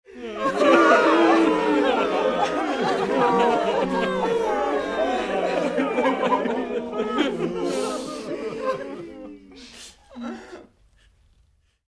دانلود صدای گریه مردم از ساعد نیوز با لینک مستقیم و کیفیت بالا
جلوه های صوتی
برچسب: دانلود آهنگ های افکت صوتی انسان و موجودات زنده دانلود آلبوم صدای گریه دسته جمعی از افکت صوتی انسان و موجودات زنده